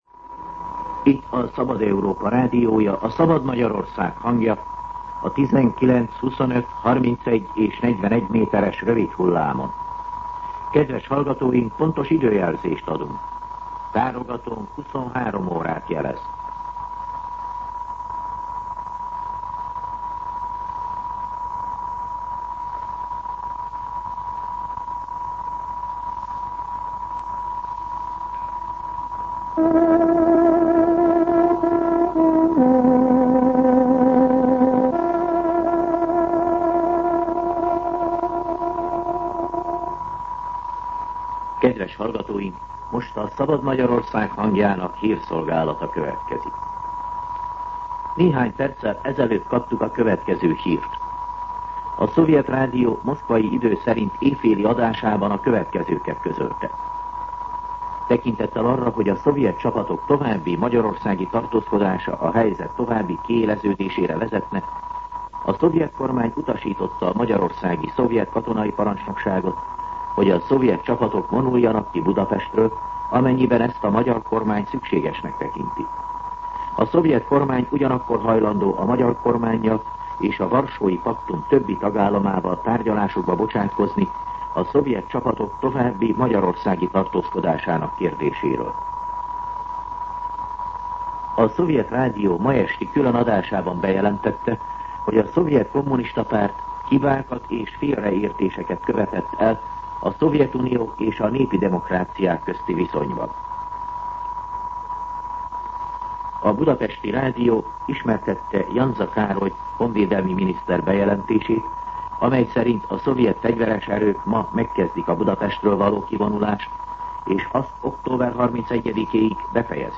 23:00 óra. Hírszolgálat